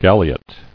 [gal·i·ot]